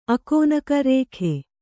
♪ akōnaka rēkhe